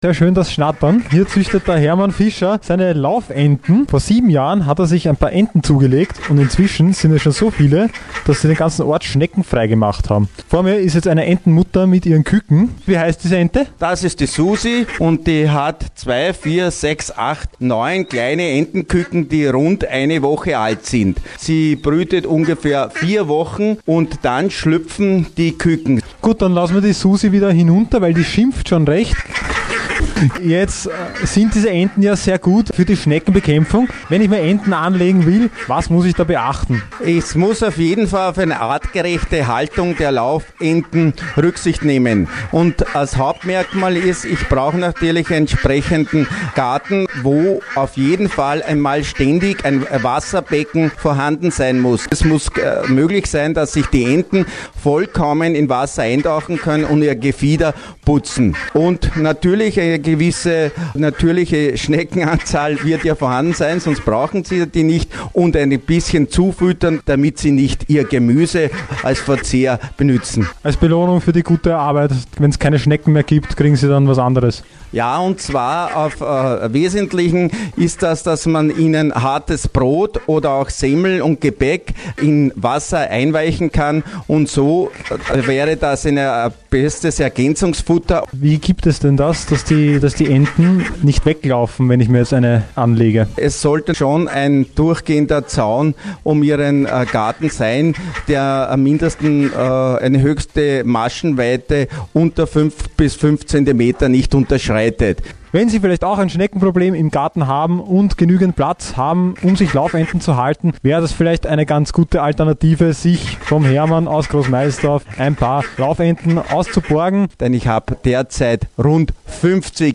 Juli 2013 -  Reportage �ber Laufenten auf Radio 88,6